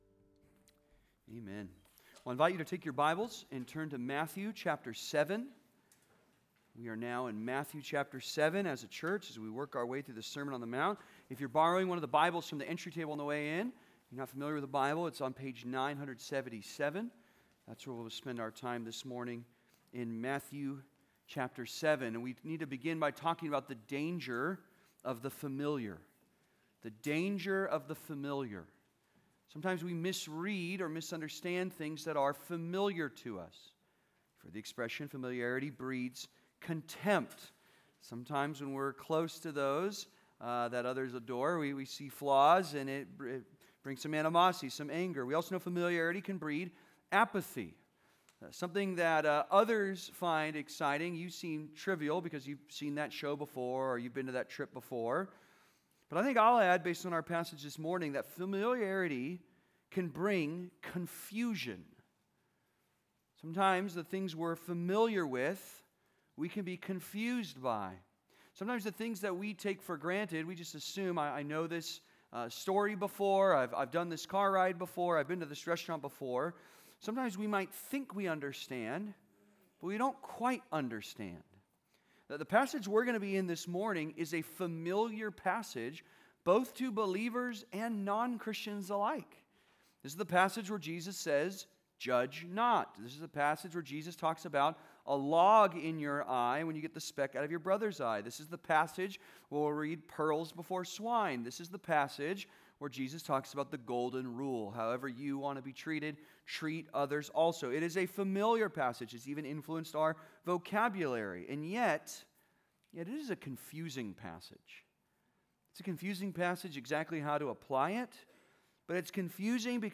(Sermon) - Compass Bible Church Long Beach